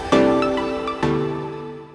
menu-back-click.wav